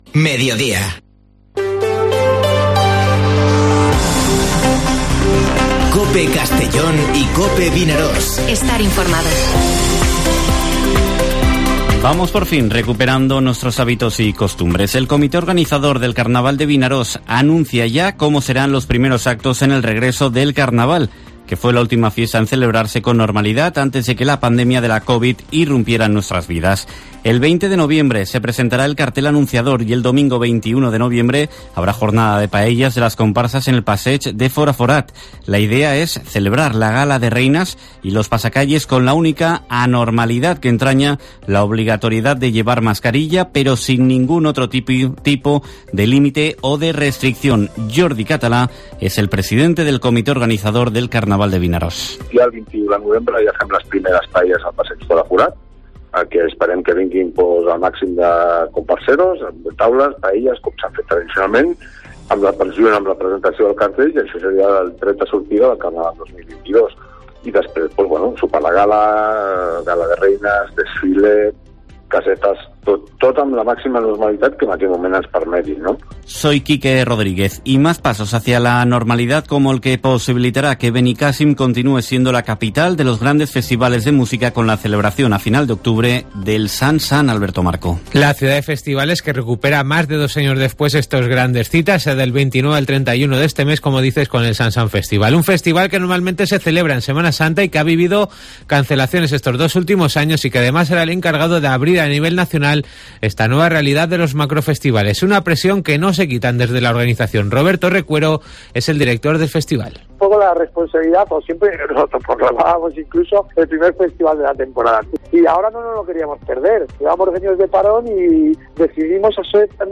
Informativo Mediodía COPE en la provincia de Castellón (15/10/2021)